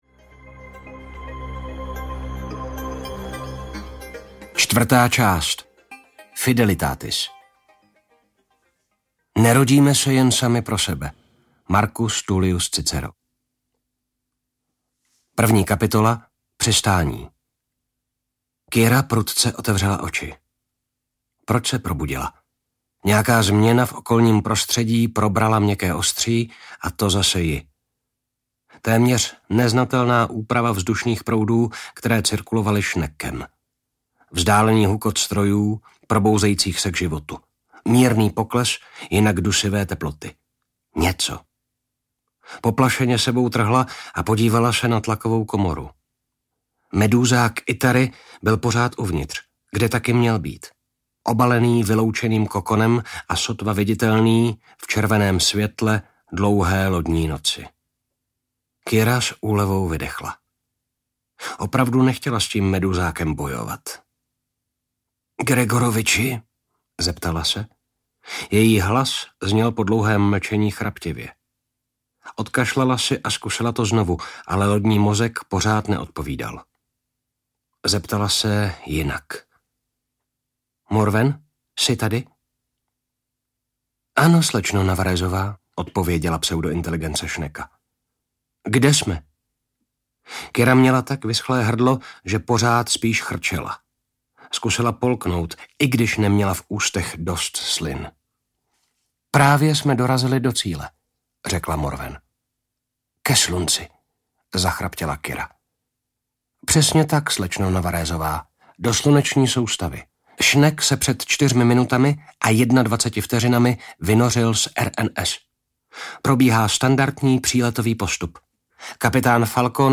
Spát v moři hvězd - Kniha II. audiokniha
Ukázka z knihy